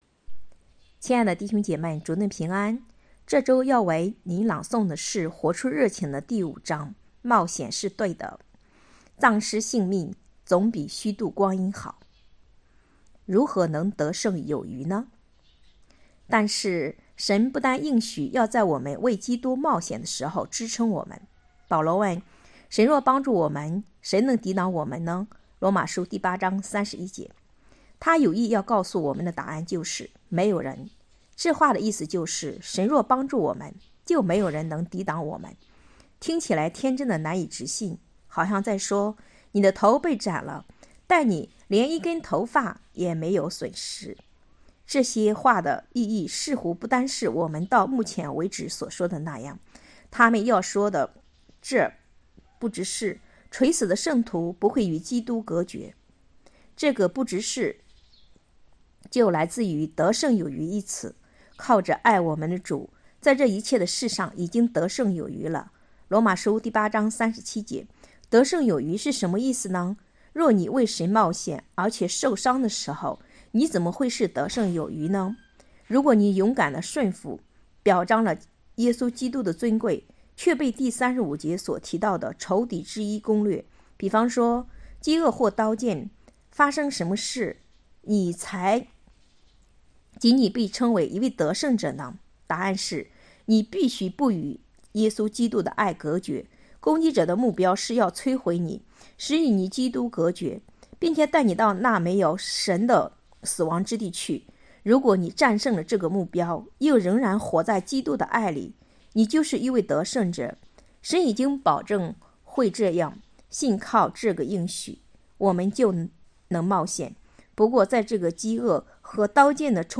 2024年3月27日 “伴你读书”，正在为您朗读：《活出热情》 欢迎点击下方音频聆听朗读内容 https